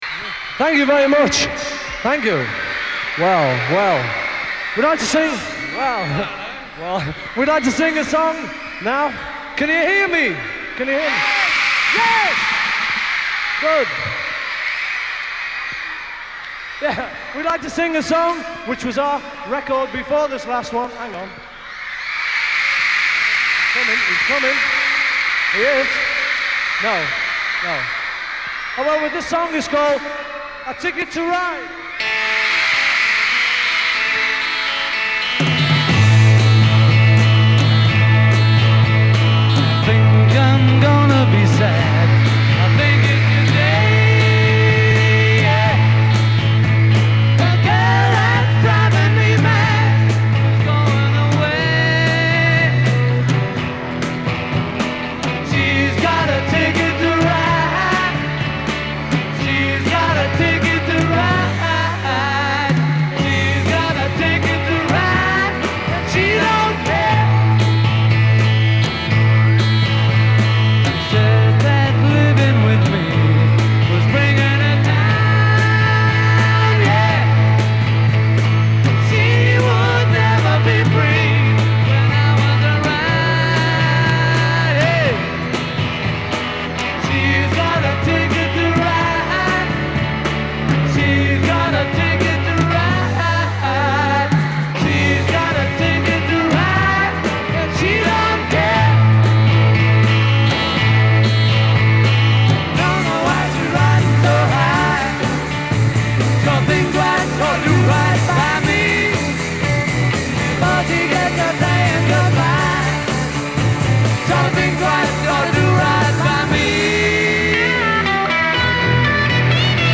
virtually unlistenable
crowd noise